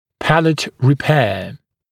[‘pælət rɪ’peə][‘пэлэт ри’пэа]восстановление нёба